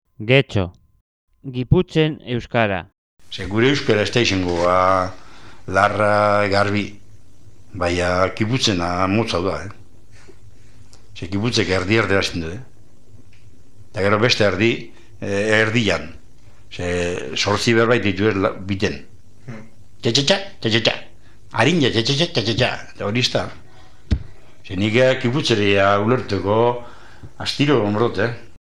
1.1. GETXO